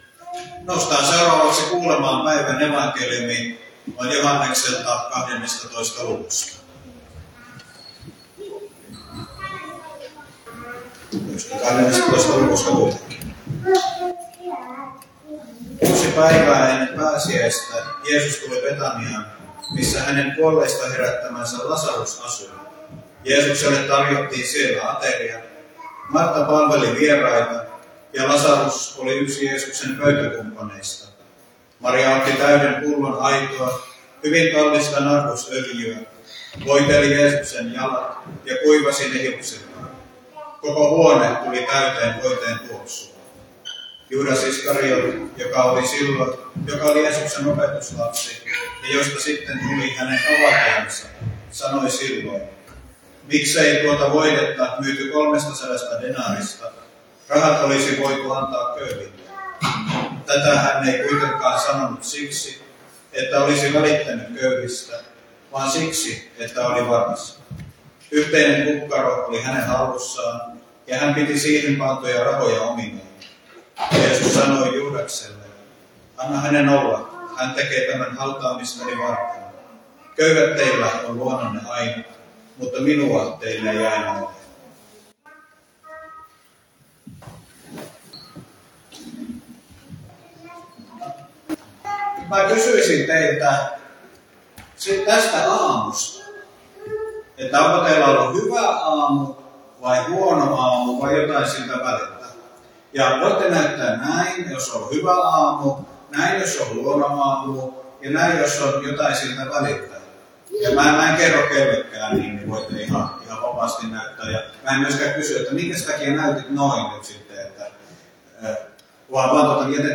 saarna Soinissa palmusunnuntaina Tekstinä Joh. 12: 1-8